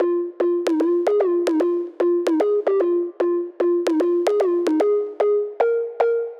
150BPM Lead 14 Fmin.wav